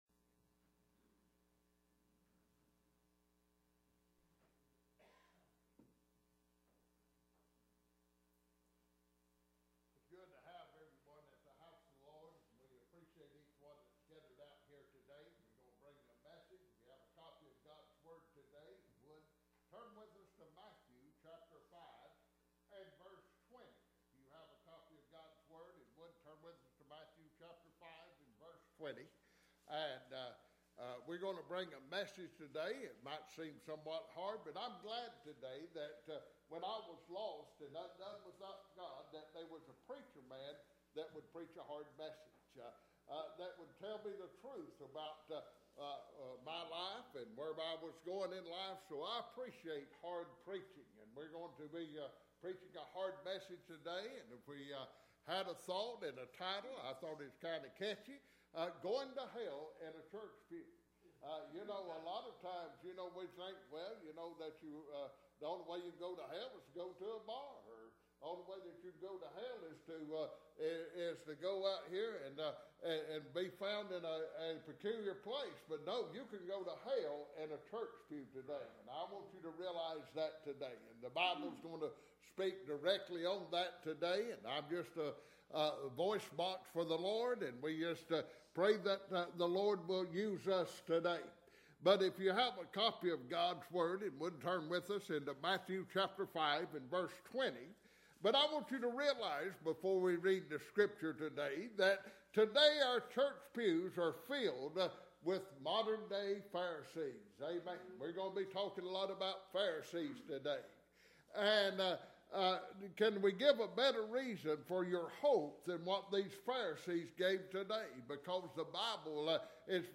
Bible Text: Matthew 5:20 | Preacher